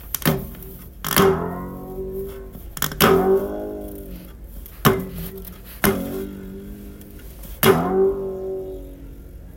Bass.mp3